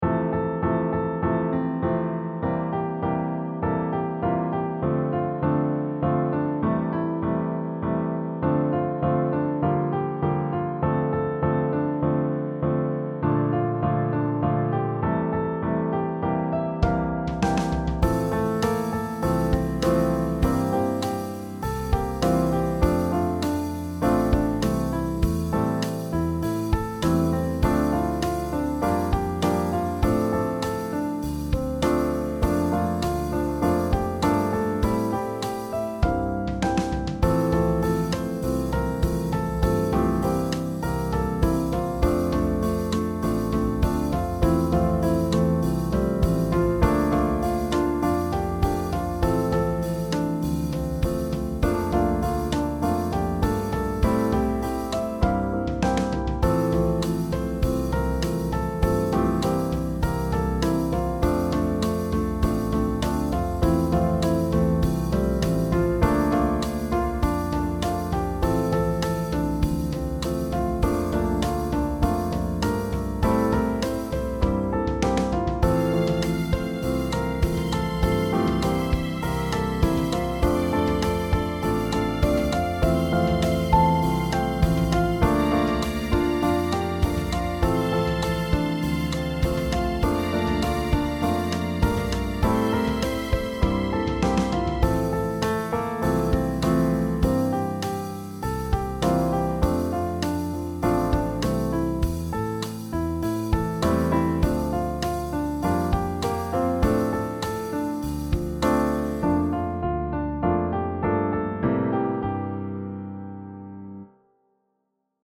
R&BBallad